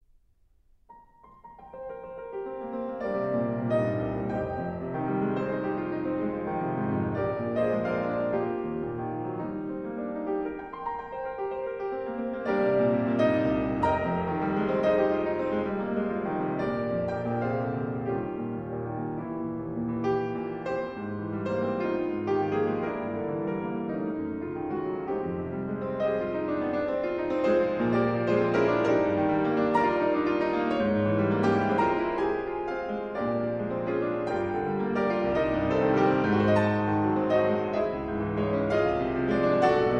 in D minor: Andante con moto